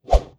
Close Combat Swing Sound 77.wav